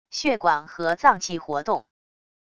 血管和脏器活动wav音频